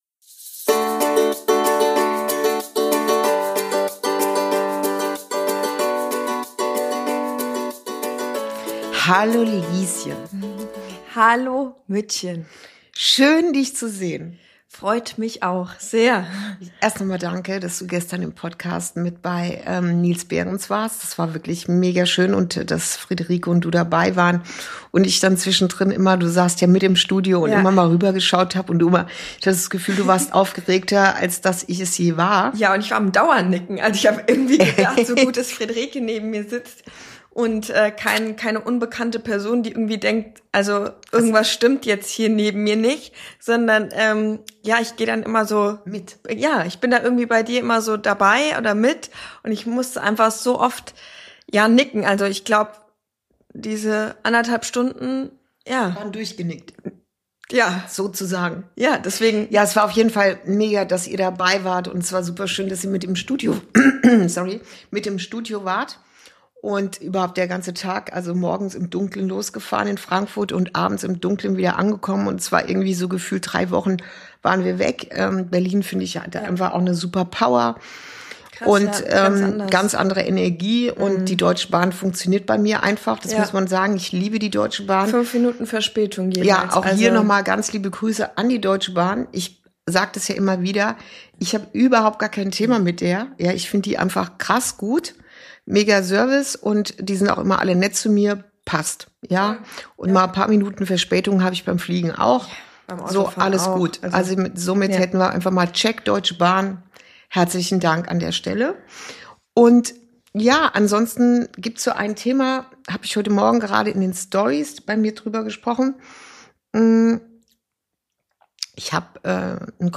Folge 43: Raus aus der Bewertung – Wenn Tiefe leicht wird ~ Inside Out - Ein Gespräch zwischen Mutter und Tochter Podcast